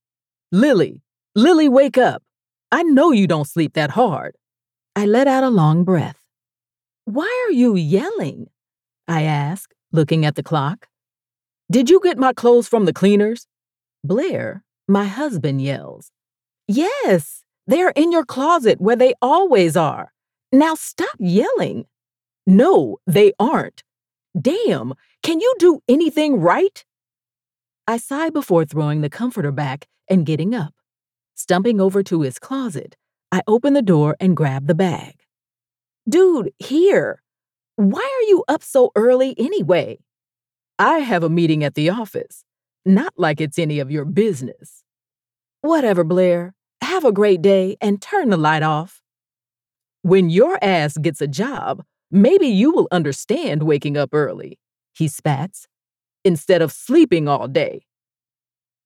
F/M 1st POV Contemporary Romance
My voice is often described as warm and feminine, with a depth that brings a unique richness to every story I narrate.
I believe in delivering high-quality audio, and to achieve this, I use top-notch home studio equipment, including a Double-Walled VocalBooth, Neumann TLM 102, RØDE NT1 5th Gen, Sennheiser 416, and a Focusrite Scarlett 2i2 interface.